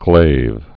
(glāv)